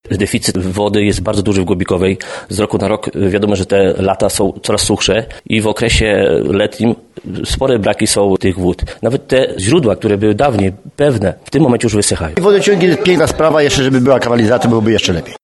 W miejscowościach Głobikowej i Braciejowej przysiółek Południk powstanie 27 km sieci, która zaopatrzy w wodę ponad 150 gospodarstw. Nareszcie wkroczymy w XXI wiek – zaznaczają mieszkańcy.